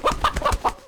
CosmicRageSounds / ogg / general / combat / creatures / CHİCKEN / he / hurt3.ogg
hurt3.ogg